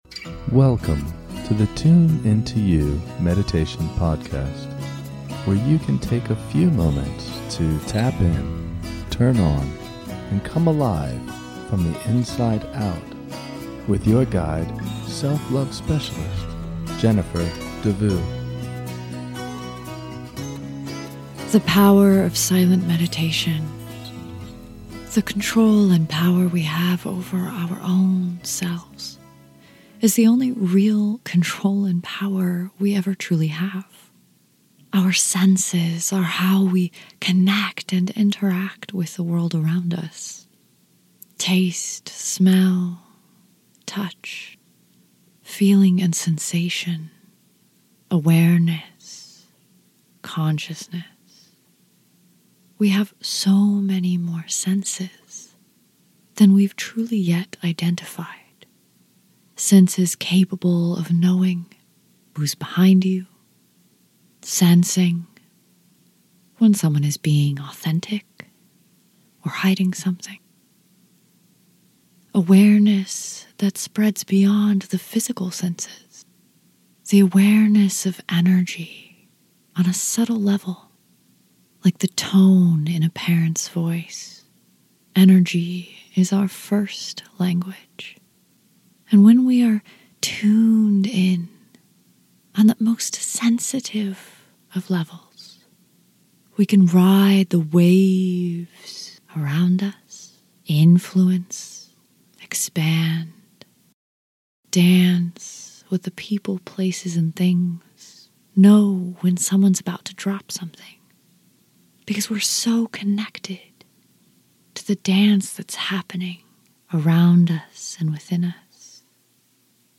In this short guided meditation, we will take a journey into your inner space to reconnect with the first language; energy. We can learn to increase our awareness, and therefore our power, presence, and peace when we practice mindful meditation.